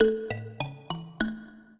mbira
minuet7-8.wav